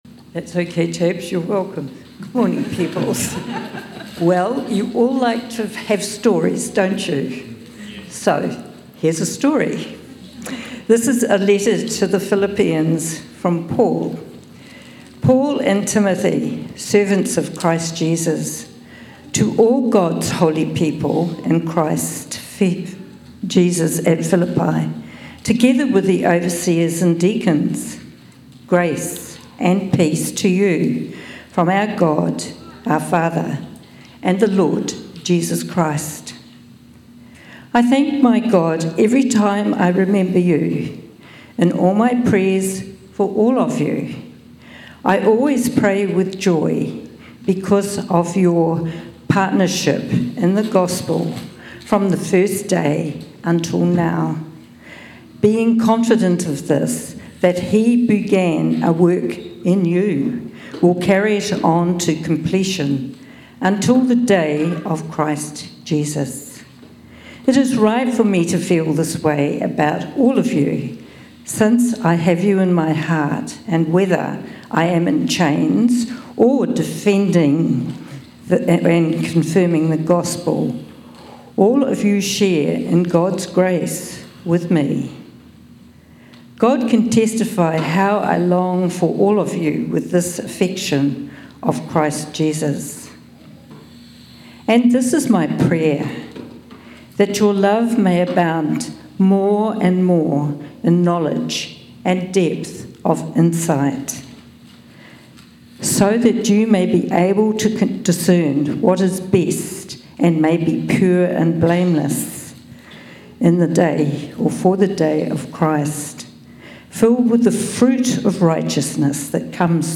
Sermons | Whanganui Anglicans